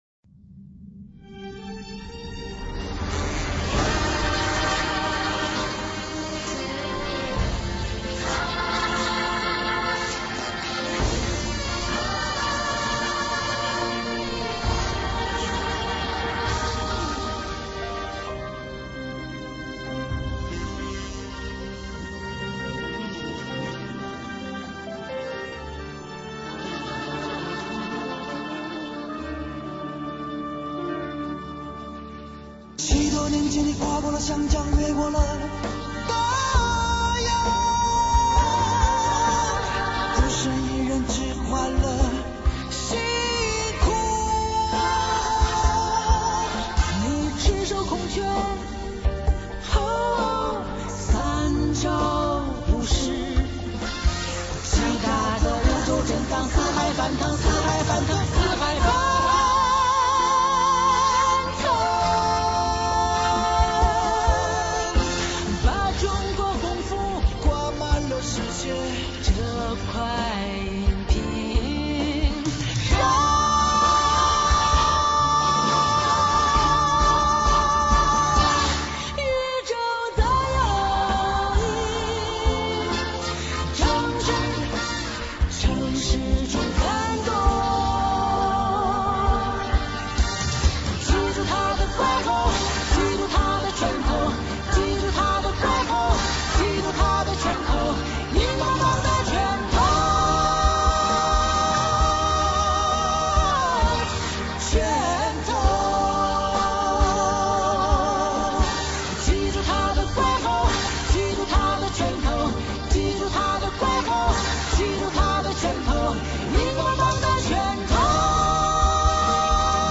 片尾歌曲